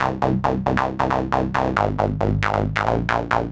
Index of /musicradar/future-rave-samples/136bpm